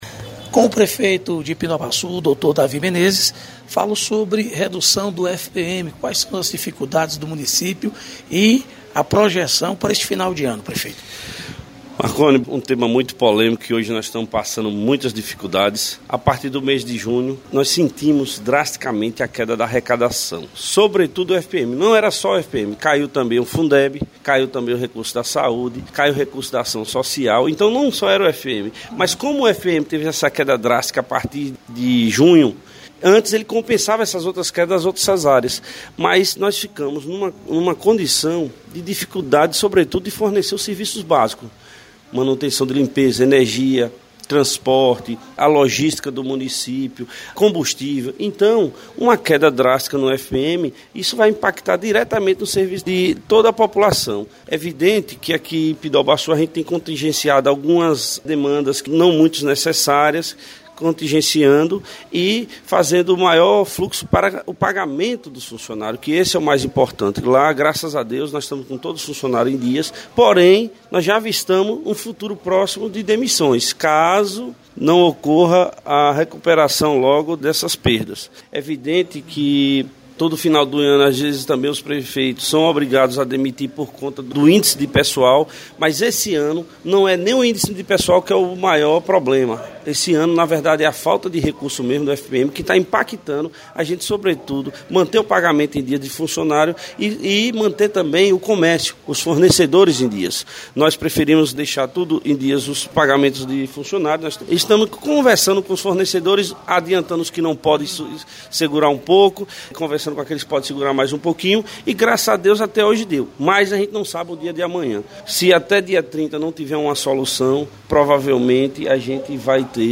Prefeito de Pindobaçu Davi Menezes, faz avaliação sobre a redução do FPM